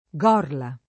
Gorla [ g0 rla ]